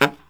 LOHITSAX06-L.wav